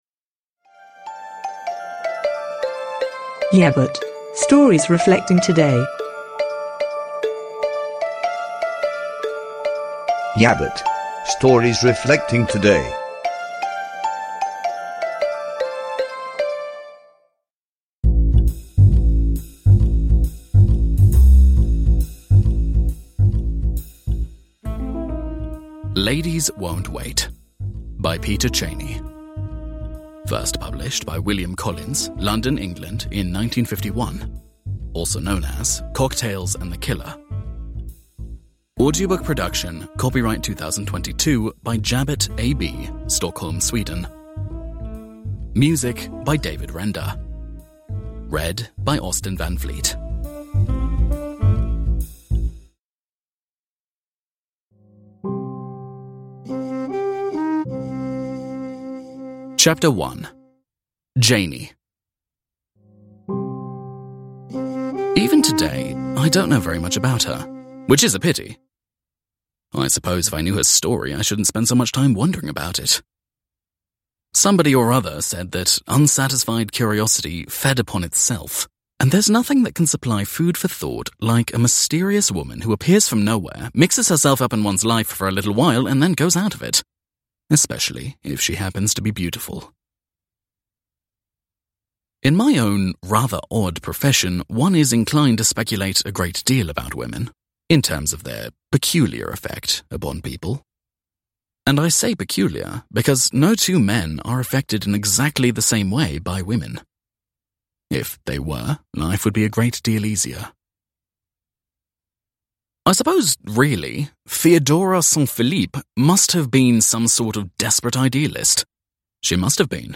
Ladies won't wait – Ljudbok – Laddas ner